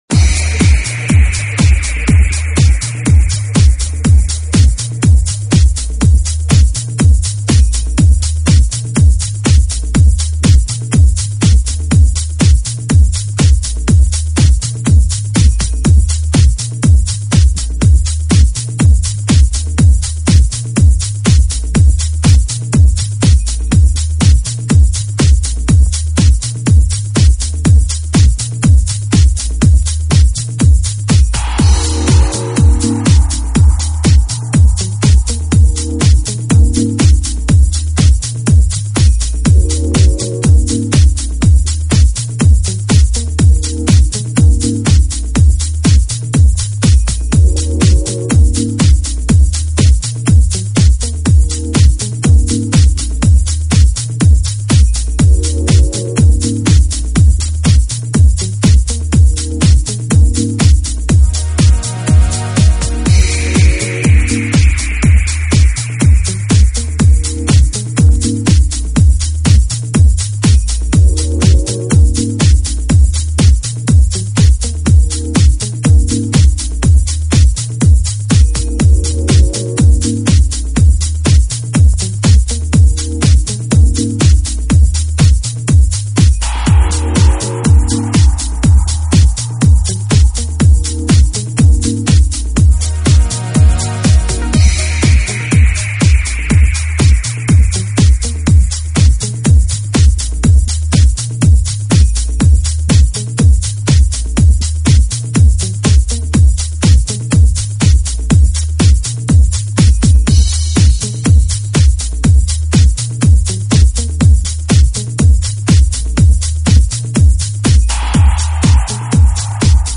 deep cool